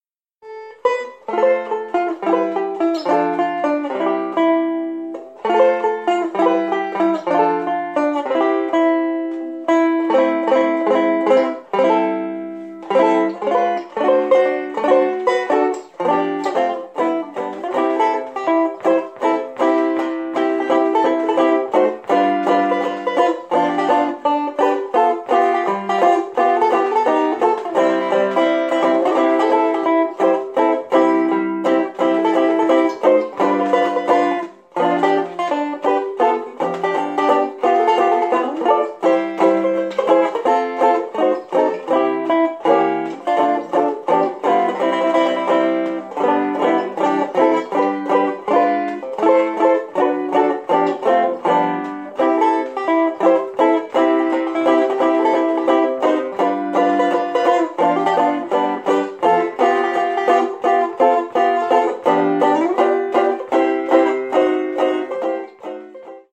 8-beat intro.
This song is in the key of C. The verse is a slower tempo.